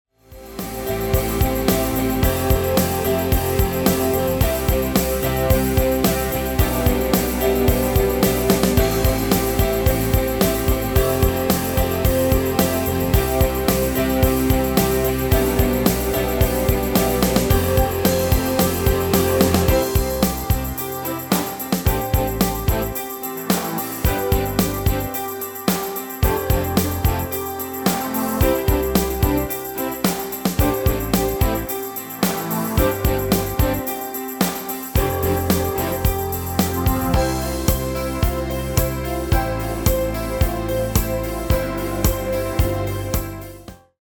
U koopt een GM-Only midi-arrangement inclusief:
- GM = General Midi level 1
- Géén vocal harmony tracks
Demo's zijn eigen opnames van onze digitale arrangementen.